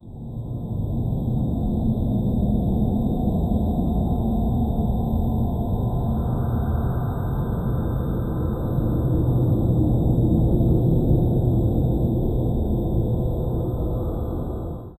monster sounds that are unnerving and analog horror type sounds while wind blows, the monster sounds are sutile and difficult to hear but they are there, and it is creepy along with clock sounds and crickets, something is lurking 0:15 Created Jul 2, 2025 11:31 PM
monster-sounds-that-are-u-tkkp34xi.wav